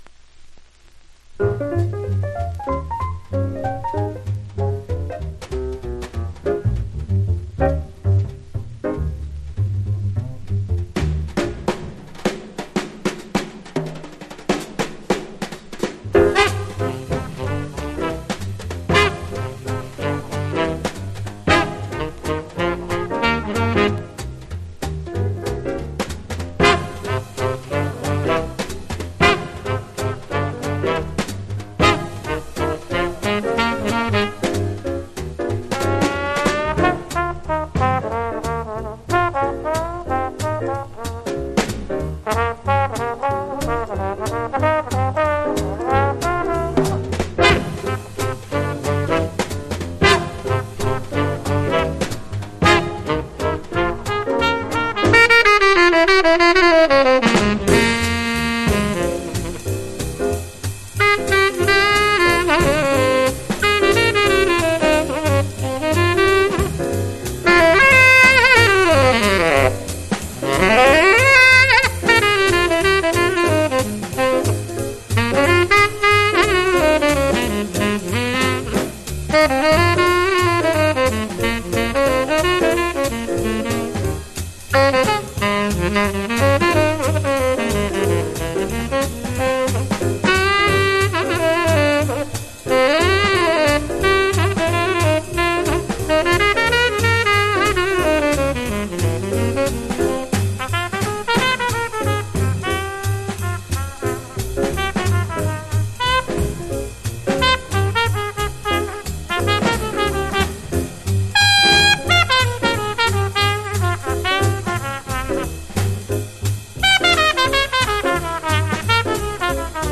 （プレス・小傷によりチリ、プチ音ある曲あり）
Genre US JAZZ